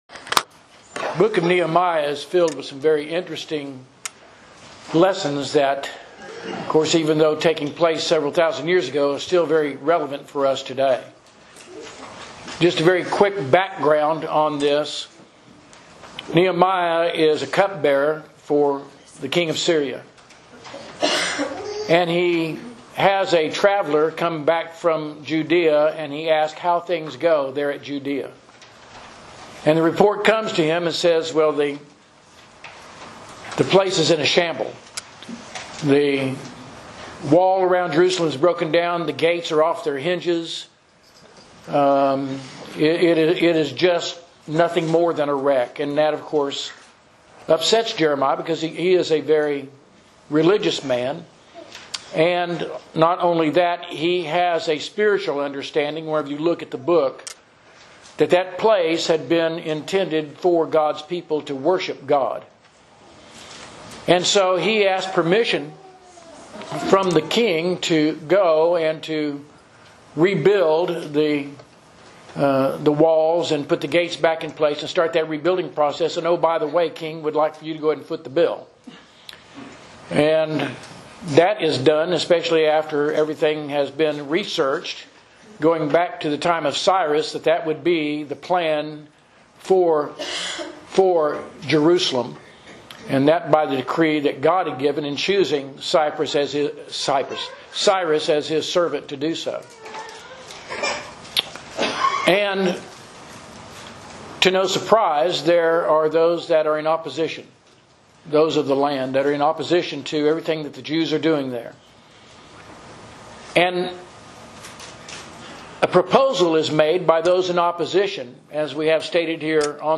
Sermons – Page 14 – South Loop church of Christ